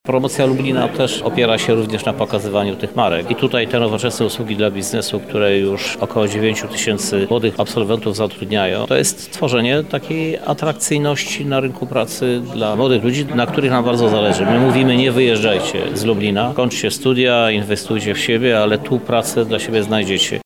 O znaczeniu inwestycji mówi Krzysztof Żuk, Prezydent Lublina: